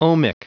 Prononciation du mot ohmic en anglais (fichier audio)